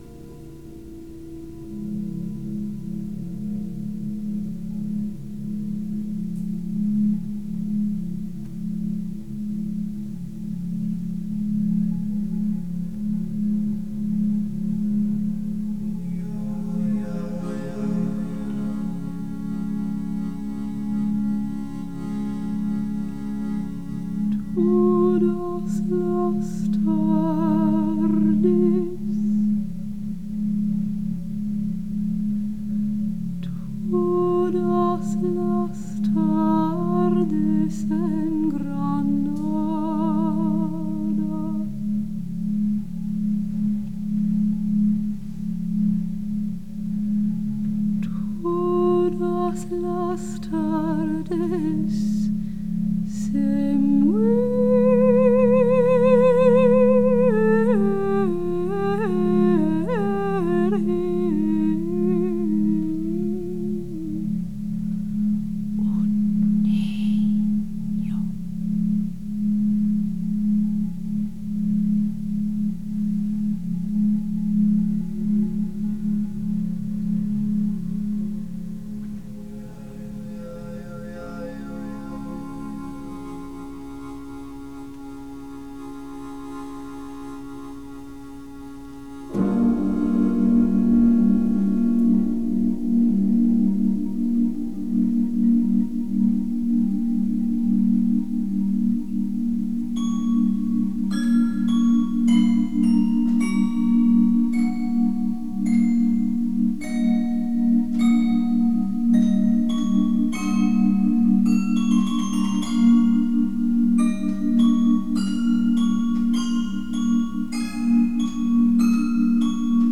made alien once again by being played on a toy piano.